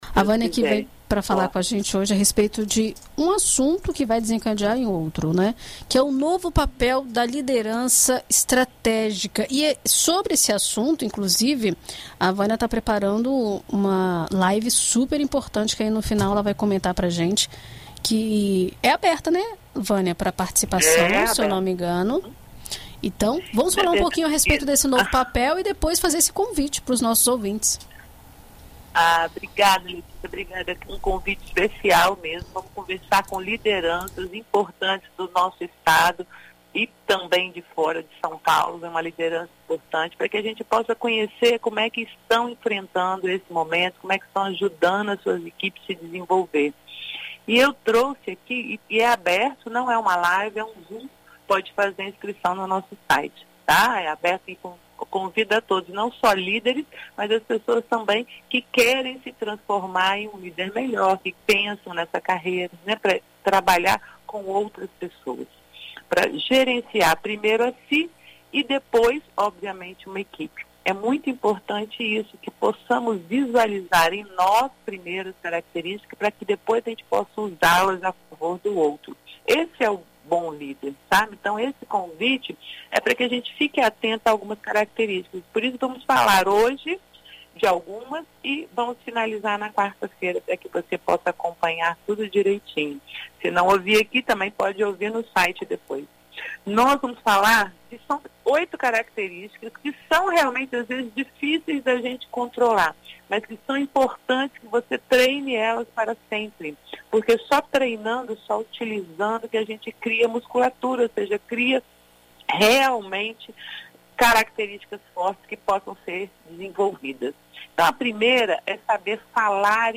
Na coluna Vida e Carreira desta segunda-feira (20), na BandNews FM Espírito Santo